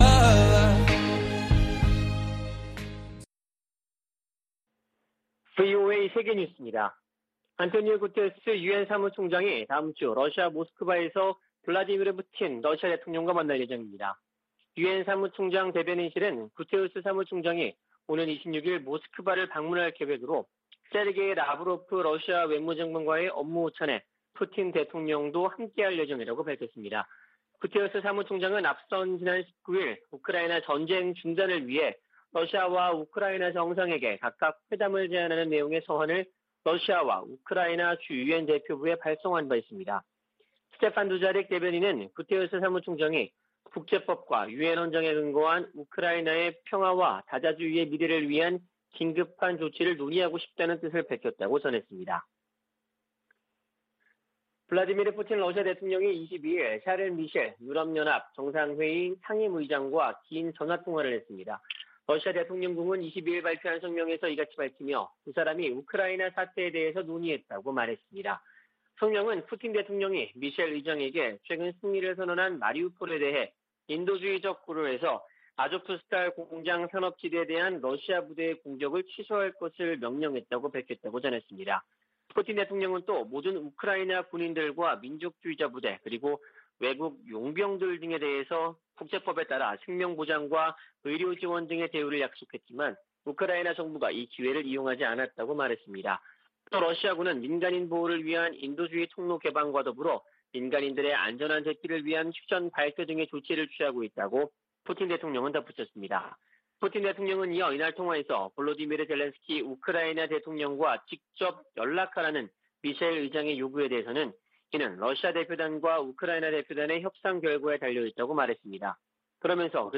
VOA 한국어 아침 뉴스 프로그램 '워싱턴 뉴스 광장' 2022년 4월 23일 방송입니다. 유럽연합(EU)이 북한의 잇단 미사일 발사에 대응해 북한 개인 8명과 기관 4곳을 독자제재 명단에 추가했습니다. 미 국무부는 북한의 도발에 계속 책임을 물리겠다고 경고하고, 북한이 대화 제안에 호응하지 않고 있다고 지적했습니다. 문재인 한국 대통령이 김정은 북한 국무위원장과 남북 정상선언의 의미를 긍정적으로 평가한 친서를 주고 받았습니다.